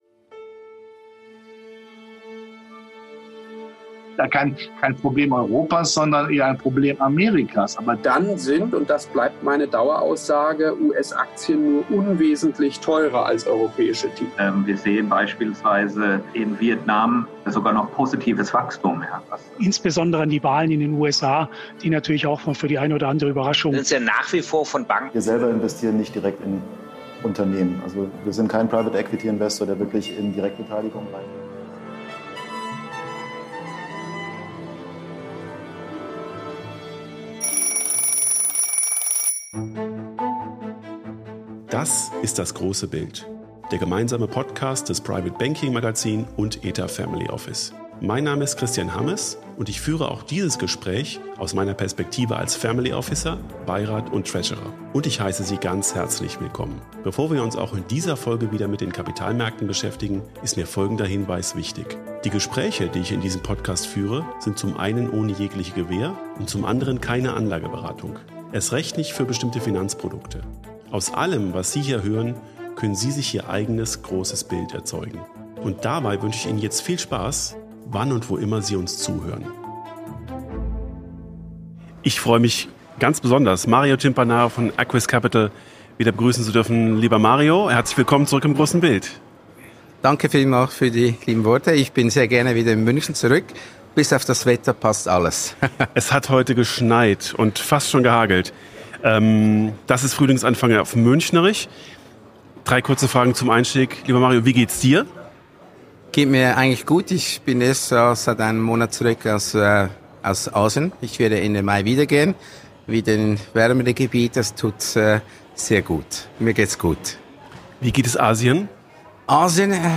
Dementsprechend eingespielt sind die beiden Kapitalmarktexperten auch in dieser Folge von „Das große Bild“, die beide auf dem private banking kongress in München aufgenommen haben.